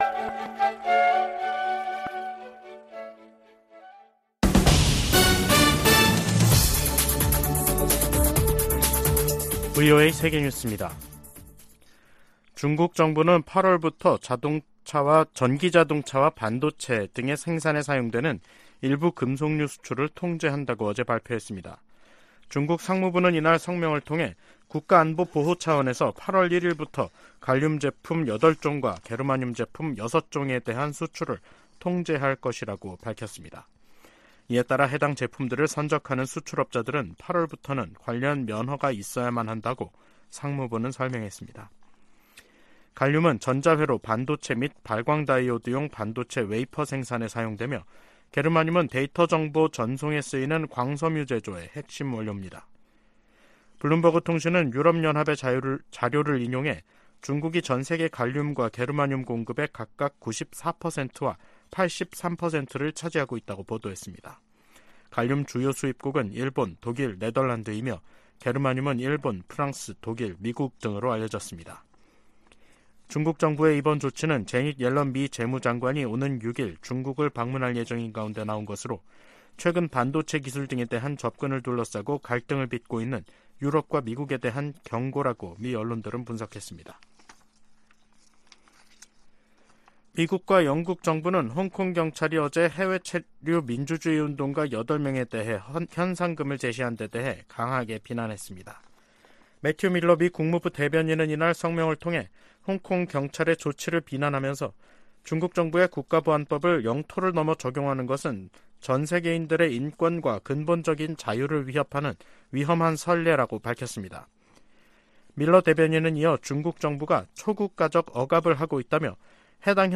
VOA 한국어 간판 뉴스 프로그램 '뉴스 투데이', 2023년 7월 4일 2부 방송입니다. 북한이 지난 2016년 2월 7일 발사한 '광명성 4호' 위성이 지구 대기권 재진입 후 소멸된 것으로 확인됐습니다. 미 핵추진 잠수함 미시간함이 한국에 이어 일본에 기항했습니다. 북한이 신종 코로나바이러스 감염증에 따른 마스크 의무를 해제한 것으로 알려지면서 국경 개방도 가능하다는 관측이 나오고 있습니다.